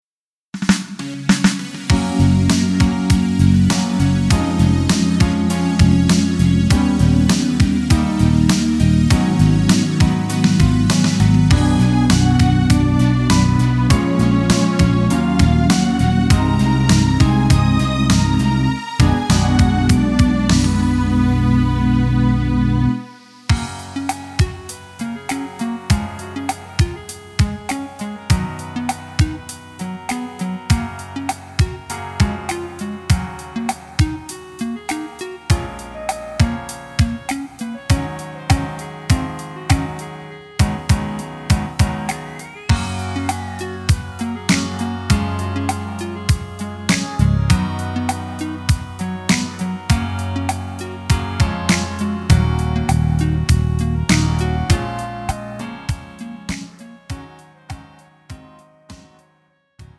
음정 남자키
장르 구분 Pro MR